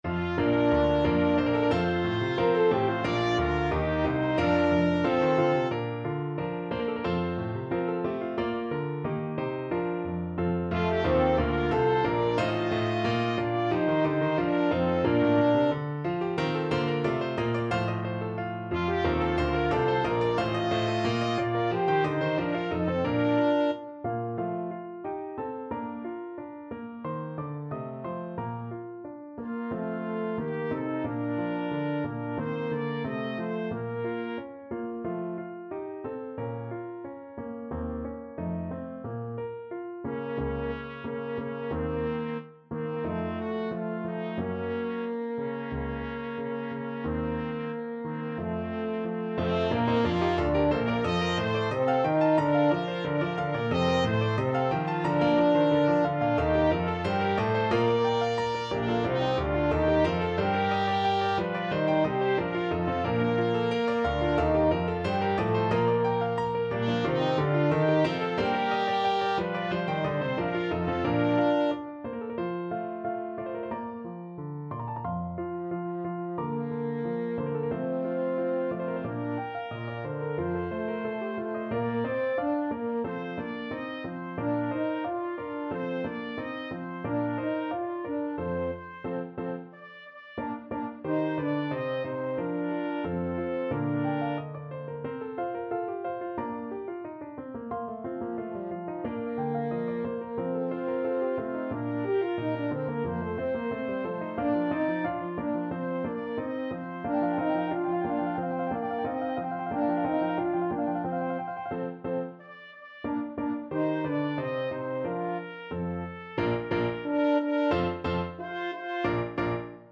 Free Sheet music for Trumpet-French Horn Duet
Bb major (Sounding Pitch) (View more Bb major Music for Trumpet-French Horn Duet )
Allegro =180 (View more music marked Allegro)
4/4 (View more 4/4 Music)
Classical (View more Classical Trumpet-French Horn Duet Music)